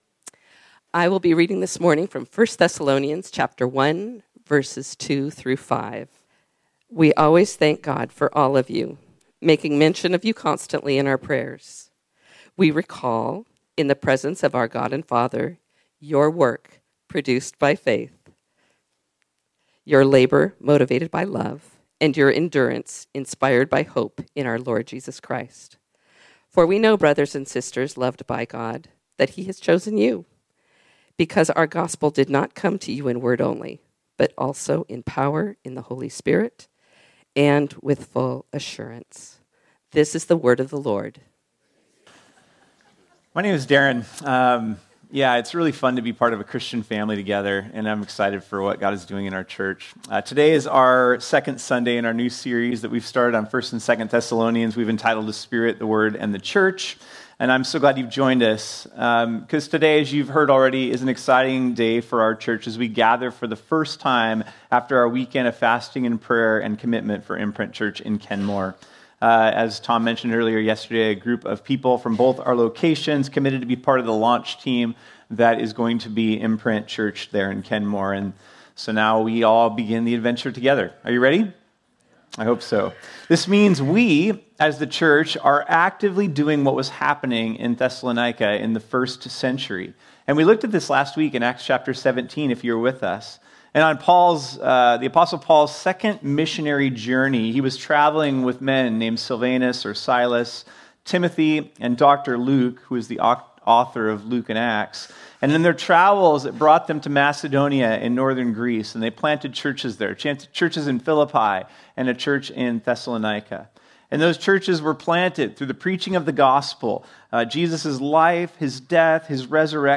This sermon was originally preached on Sunday, June 8, 2025.